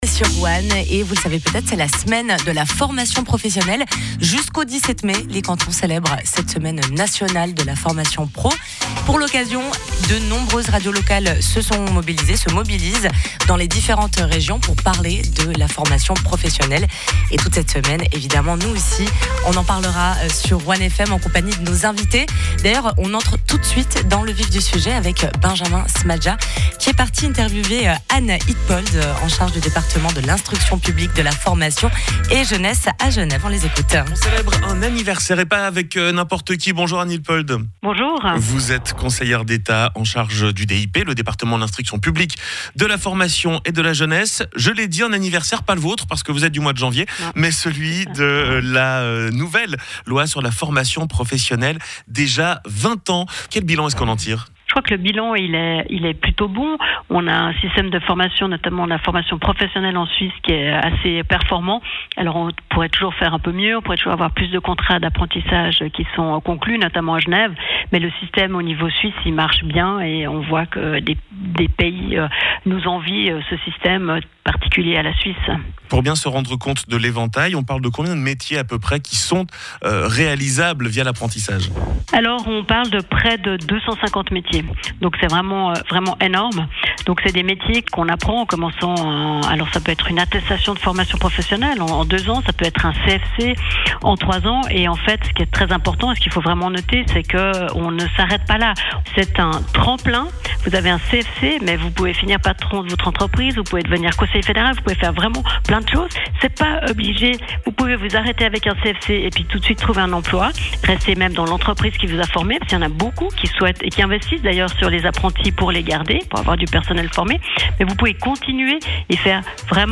Lundi 13 mai – Anne Hiltpold, conseillère d’Etat
Pour lancer cette semaine spéciale, la conseillère d’Etat Anne Hiltpold nous parle du succès que rencontre la formation professionnelle en Suisse.